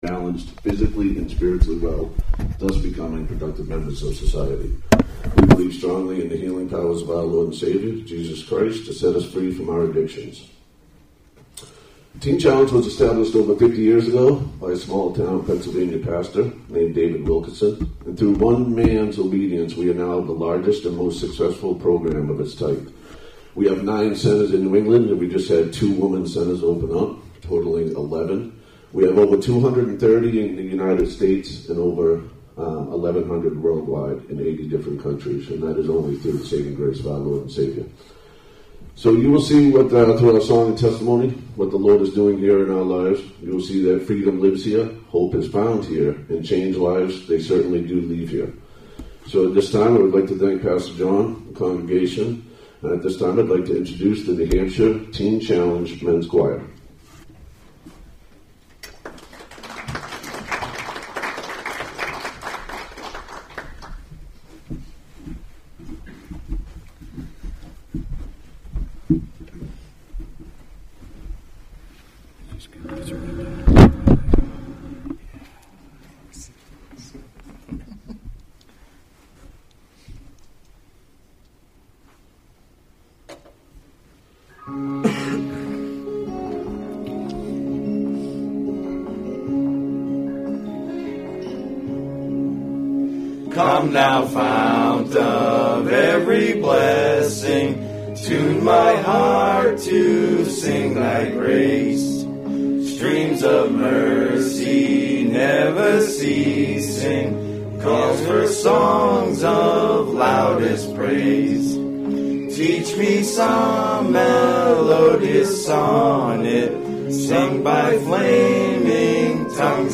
November 11, 2018 Guest Speaker Sermons series Veritas Adult Class Save/Download this sermon Various Scriptures Other sermons from Various Scriptures Several men from the Teen Challenge Manchester facility joined us for a combined Veritas (adults, High school and Middle school) class at 9:15 a.m. on Sunday, November 18th. They shared in song and testimony what God is doing in the lives of men through the gospel.